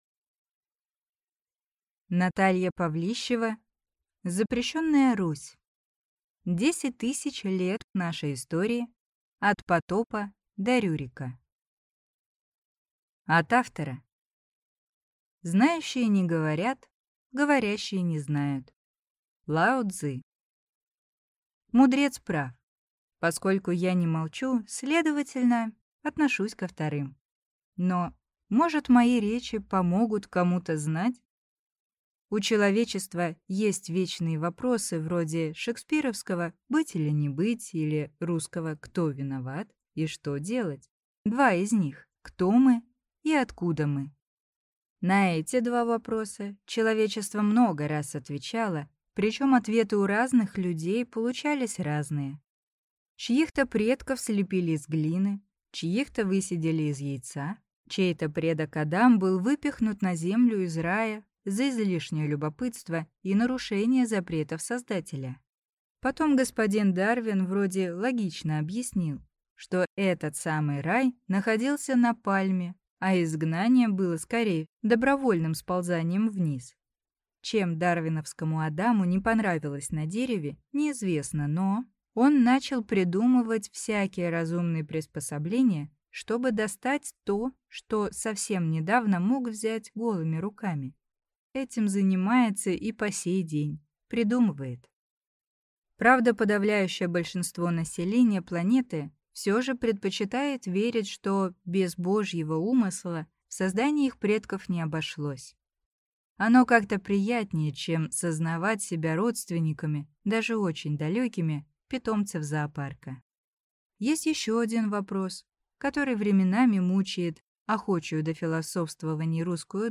Аудиокнига Запрещенная Русь. 10 тысяч лет нашей истории – от Потопа до Рюрика | Библиотека аудиокниг